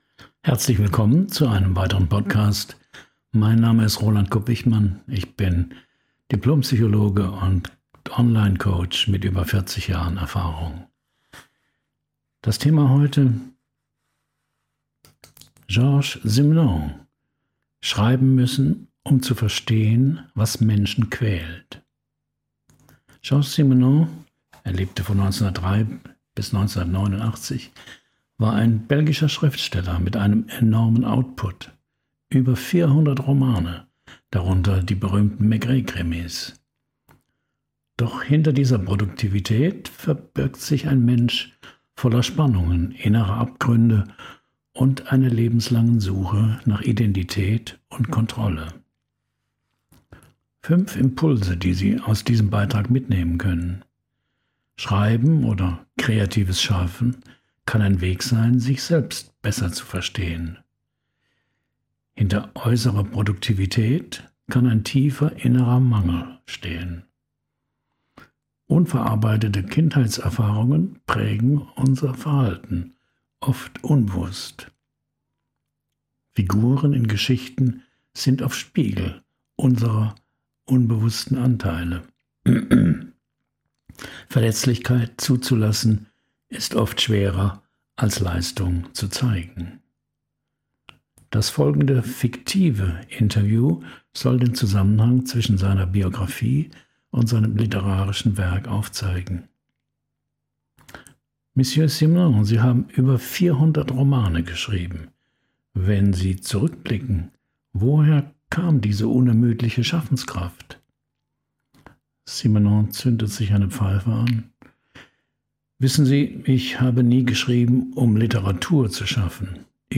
In einem **fiktiven Interview** spricht Simenon über seine Kindheit ohne Wärme, seine rastlose Suche nach Anerkennung, seine Überforderung mit Nähe – und darüber, warum er wirklich schrieb. Diese Folge ist keine Biografie, sondern eine psychologische Reise.